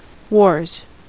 Ääntäminen
Ääntäminen US Tuntematon aksentti: IPA : /wɔɹz/ Haettu sana löytyi näillä lähdekielillä: englanti Käännöksiä ei löytynyt valitulle kohdekielelle. Wars on sanan war monikko.